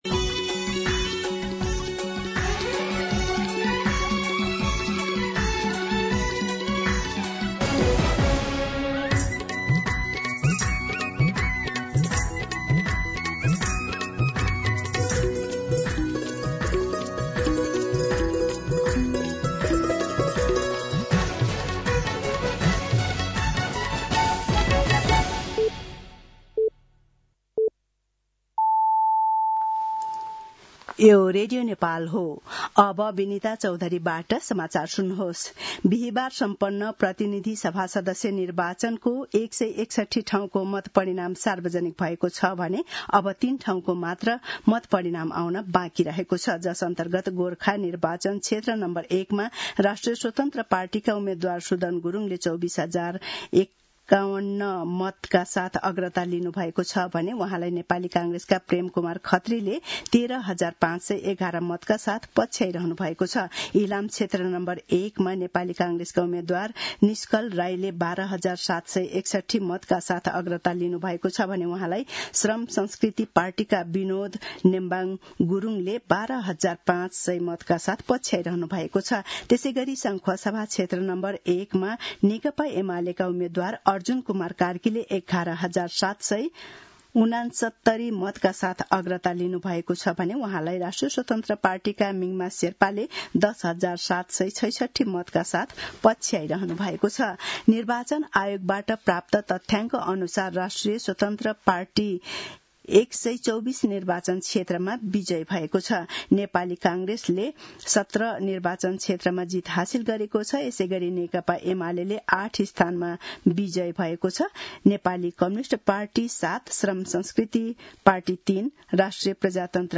दिउँसो १ बजेको नेपाली समाचार : २५ फागुन , २०८२
1-pm-Nepali-News.mp3